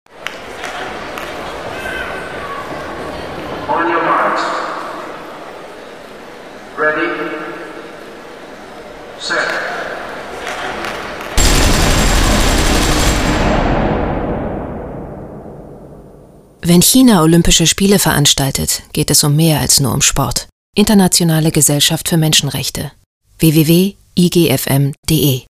Hörfunk-Spots zur VR China